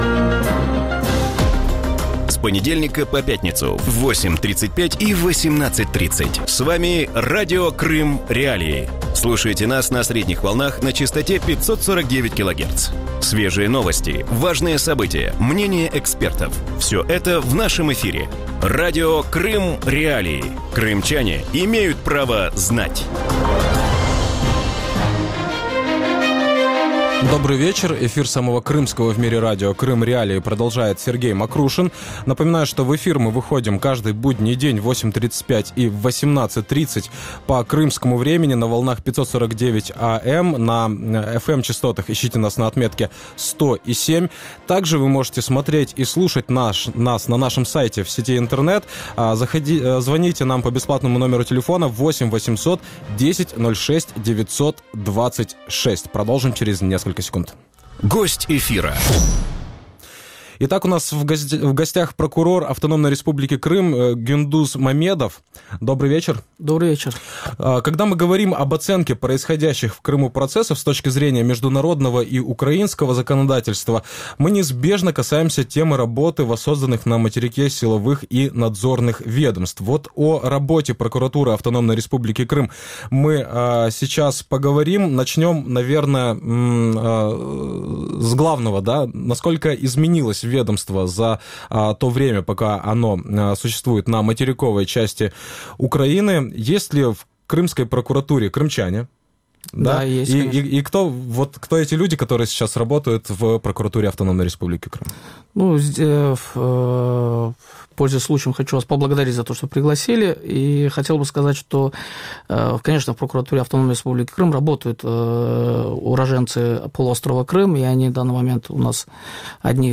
Хто відповість за Крим? Інтерв'ю з прокурором АРК
У вечірньому ефірі Радіо Крим.Реалії обговорюють діяльність прокуратури Автономної Республіки Крим. Як проходить розслідування справ про порушення прав людини в анексованому Криму?
Кого Україна вважає державними зрадниками у Криму? Гість ефіру – прокурор Автономної Республіки Крим Гюндуз Мамедов.